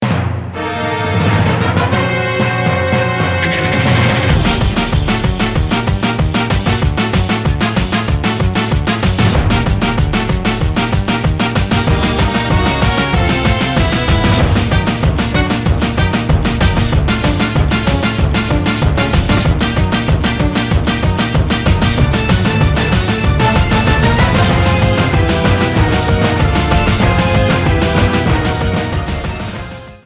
Cut off and fade-out